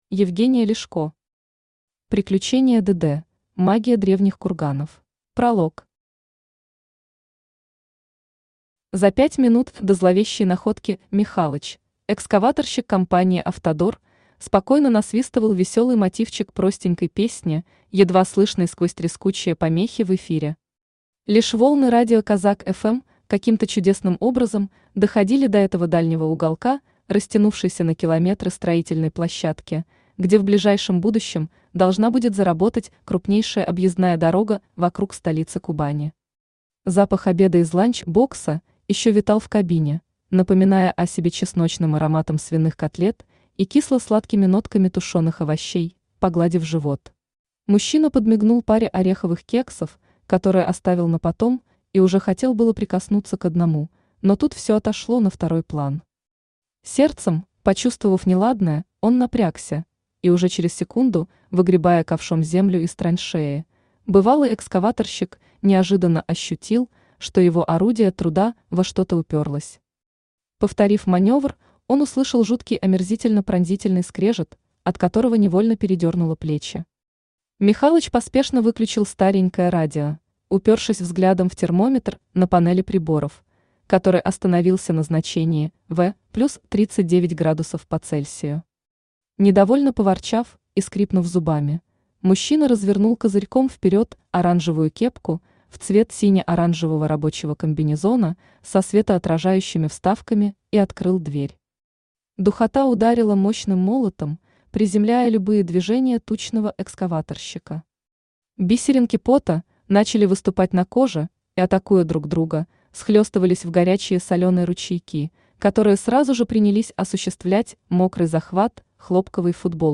Аудиокнига Приключения ДД. Магия древних курганов | Библиотека аудиокниг
Магия древних курганов Автор Евгения Ляшко Читает аудиокнигу Авточтец ЛитРес.